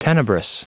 Prononciation du mot tenebrous en anglais (fichier audio)
Prononciation du mot : tenebrous